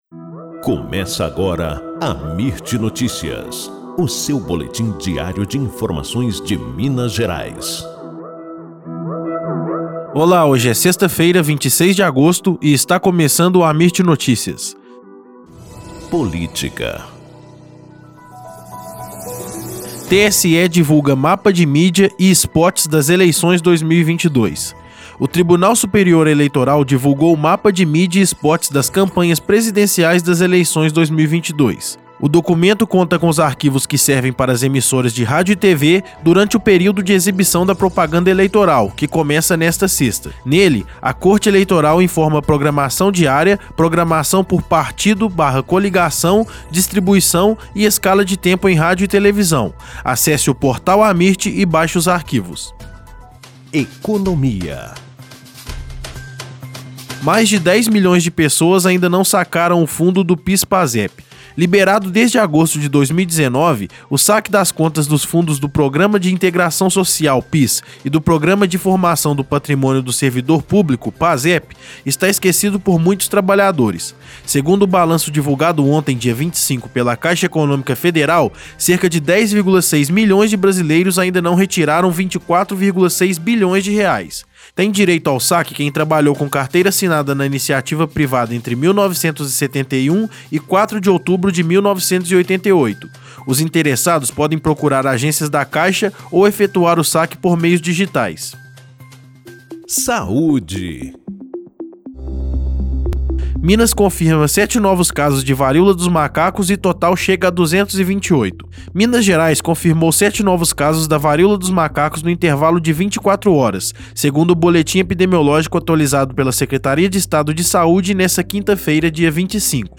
Boletim Amirt Notícias – 26 de agosto